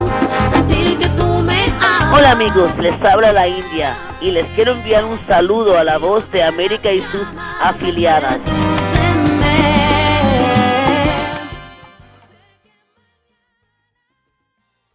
La India saluda a la Voz de América y sus afiliadas